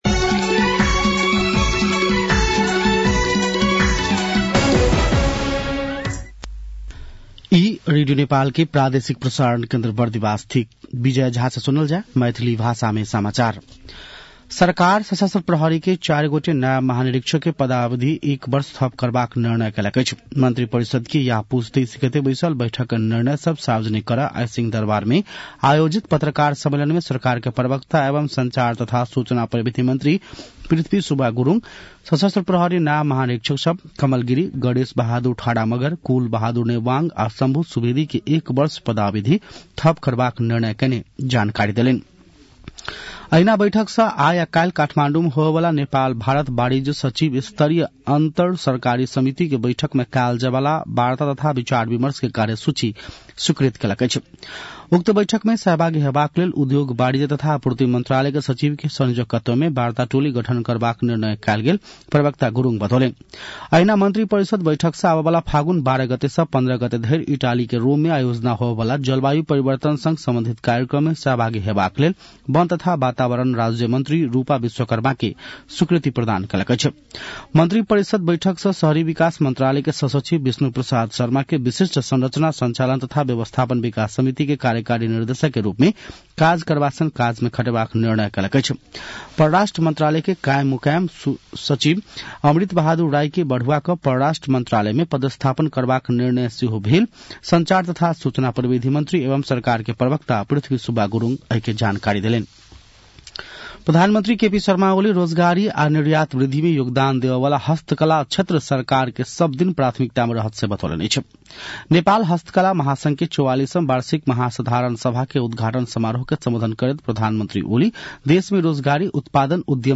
मैथिली भाषामा समाचार : २७ पुष , २०८१